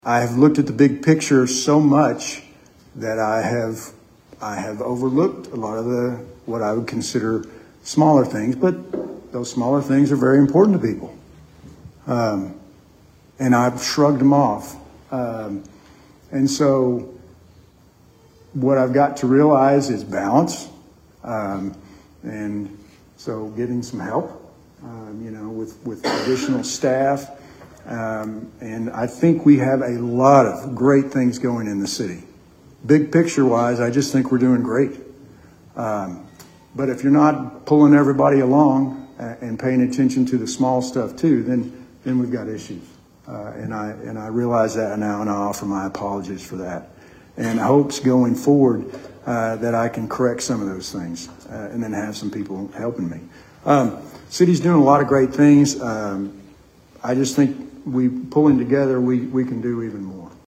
During his bi-weekly City Manager’s report, Ivester addressed the speculation about his dismissal.